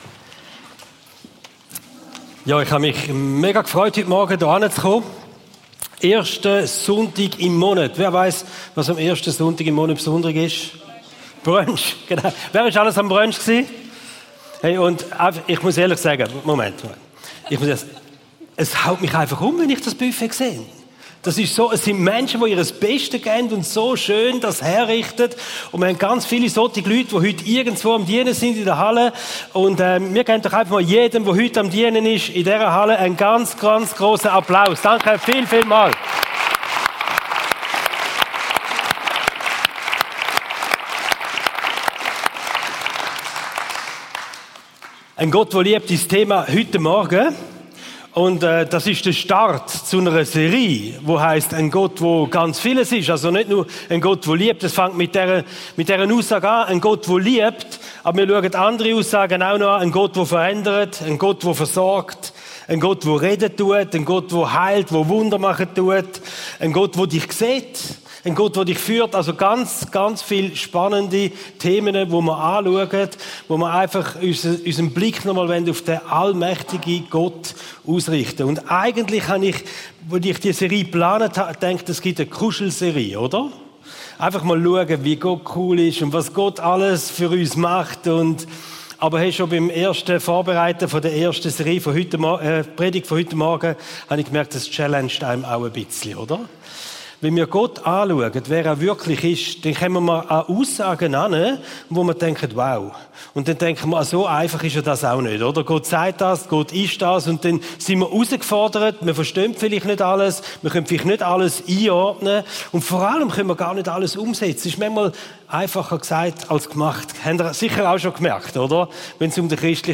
Regelmässig die neusten Predigten der GvC Frauenfeld